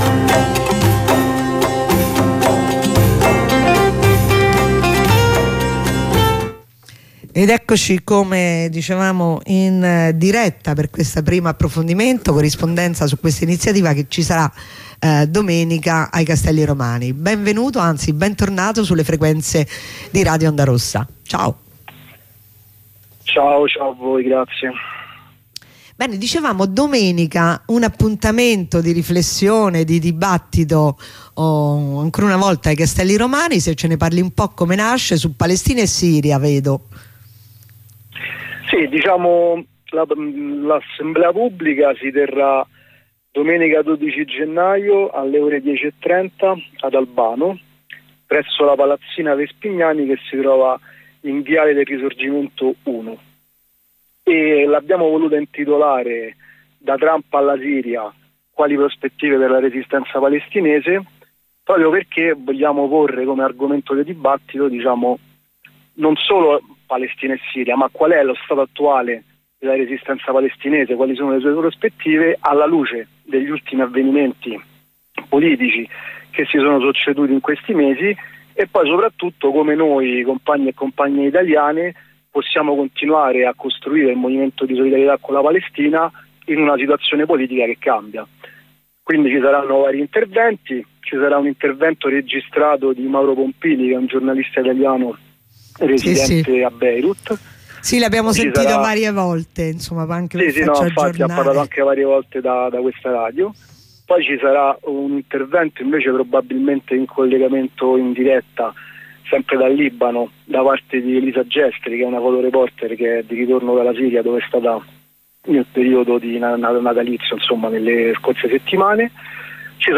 Corrispondenza con un compagno dei Castelli Romani